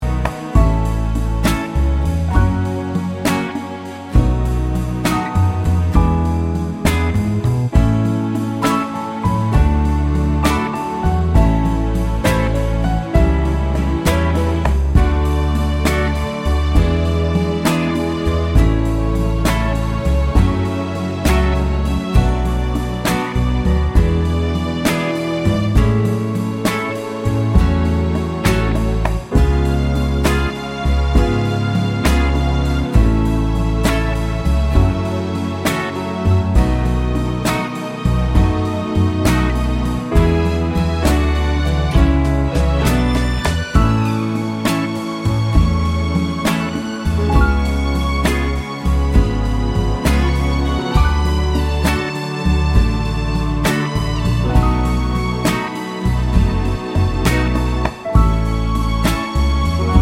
no Backing Vocals Soul / Motown 2:34 Buy £1.50